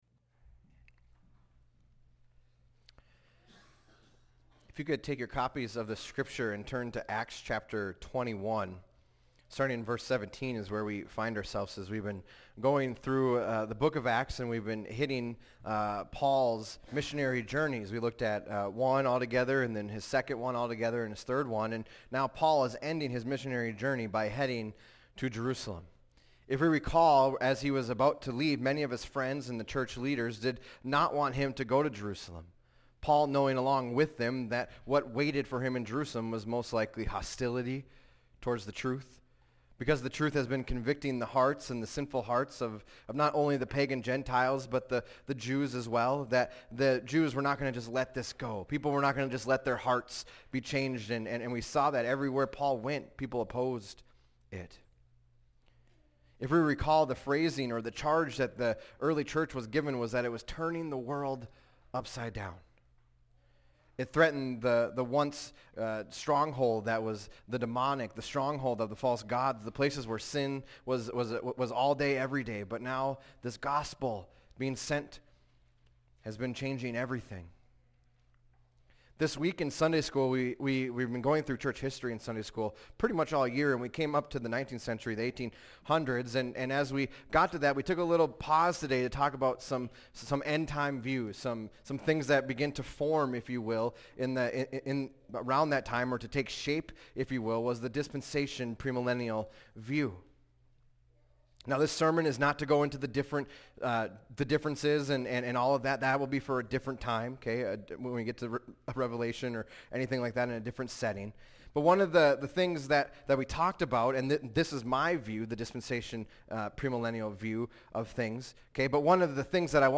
fbc_sermon_092125.mp3